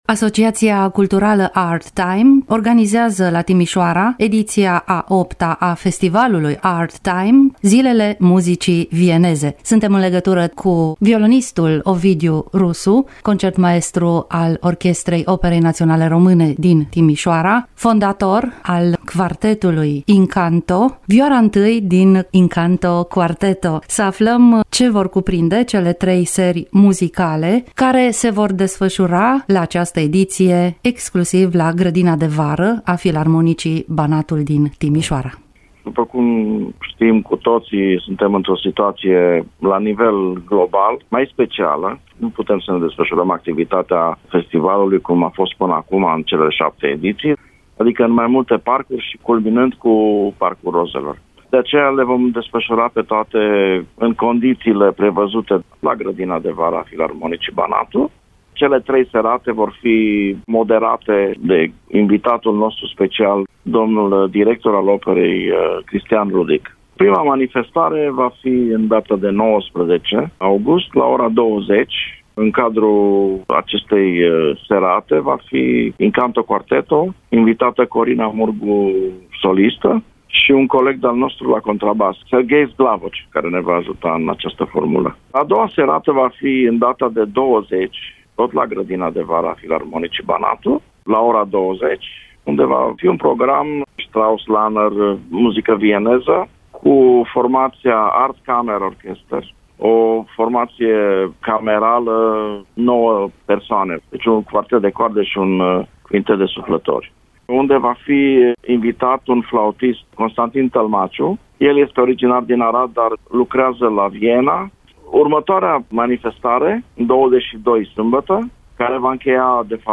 Un dialog pe această temă